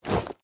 equip_chain4.ogg